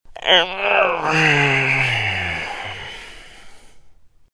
Звуки зевоты
Утренний звук подтягивания в кровати